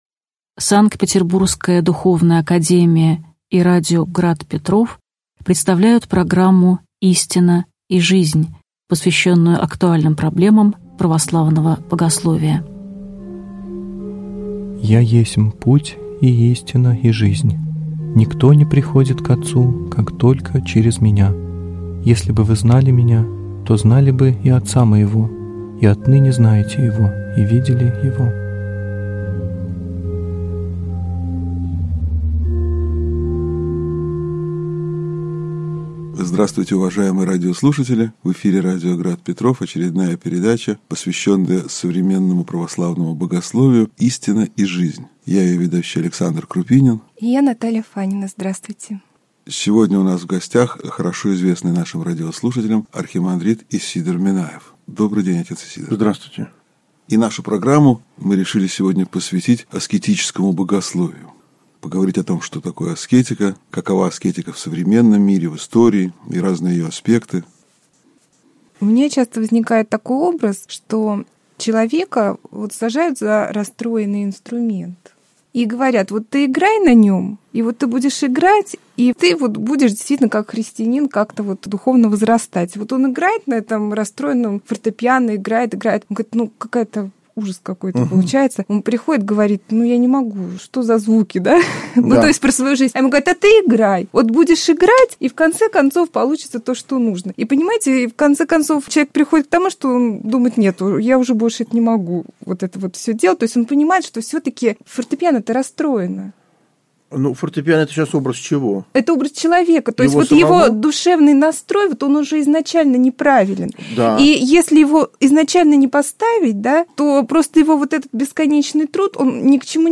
Аудиокнига Беседы об аскетике (часть 2) | Библиотека аудиокниг